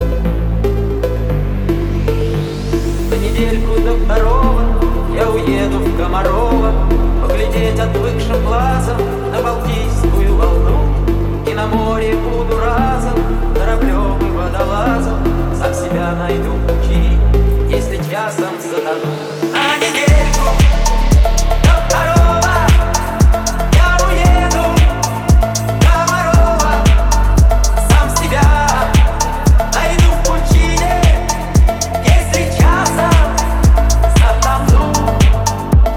,em_question, Из какой видеоигры саундтрек?